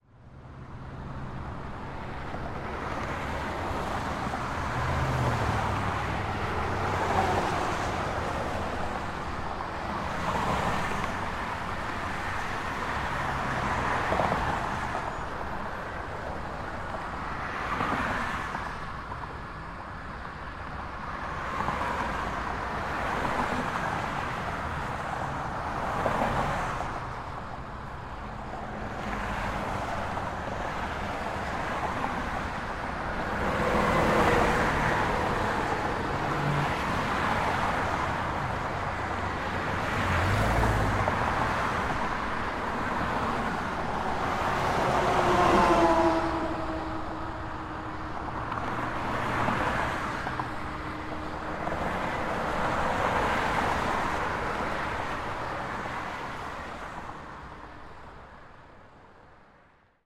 Звуки шоссе, дорог
Шум города гул машин ритм дорог